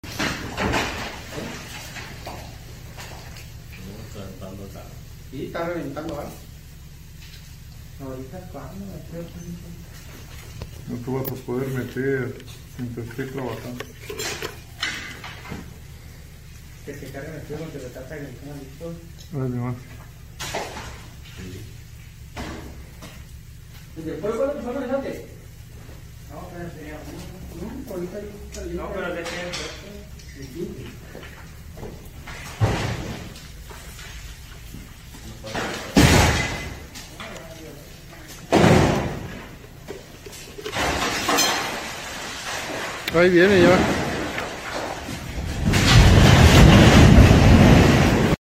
Underground mine rock fall - See Pinned Comment.
A great big amount of rock, fall from enough height - and "WHAM!" - flat like a pancake.